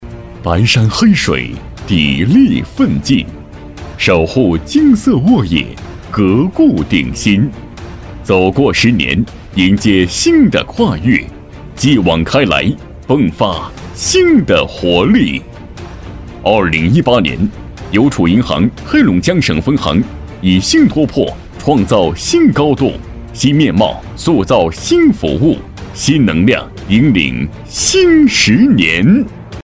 宣传片配音
激情力度 金融银行
激情有力，磁性稳重男音，擅长抗战、专题汇报、宣传片，讲述题材。